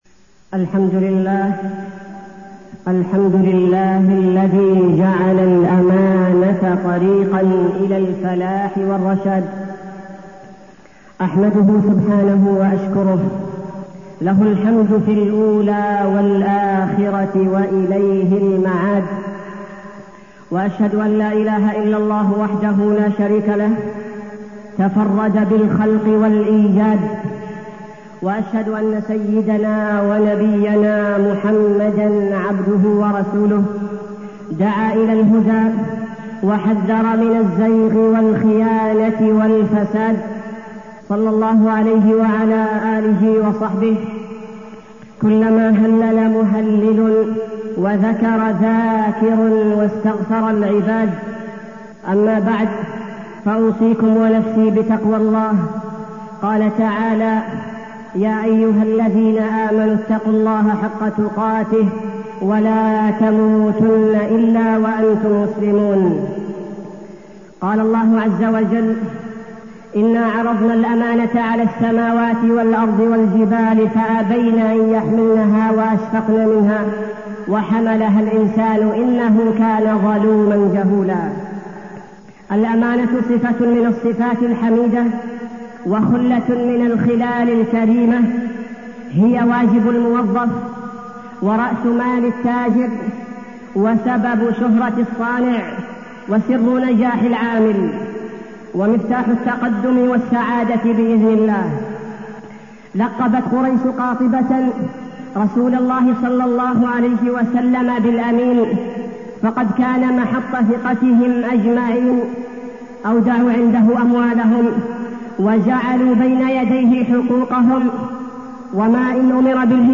تاريخ النشر ٩ جمادى الأولى ١٤٢٠ هـ المكان: المسجد النبوي الشيخ: فضيلة الشيخ عبدالباري الثبيتي فضيلة الشيخ عبدالباري الثبيتي الأمانة